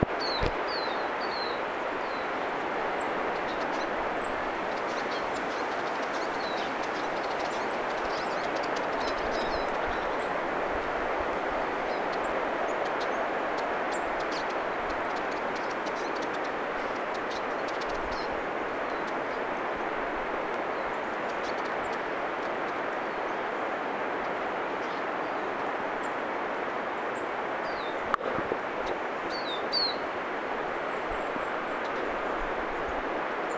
että ne toistivat tiiy-ääntä.
tiiy-vihervarpusia, 1
tiiy-vihervarpusia.mp3